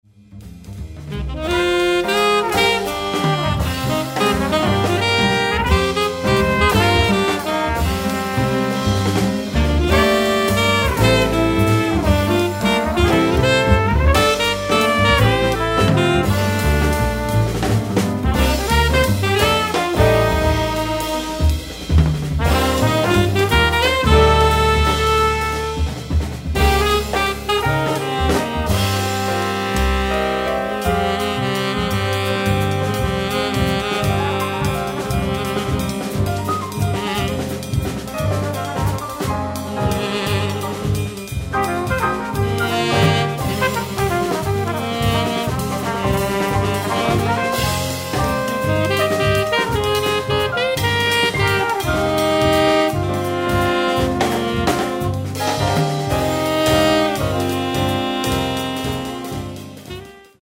tenor saxophone
acoustic bass
drums
piano
trumpet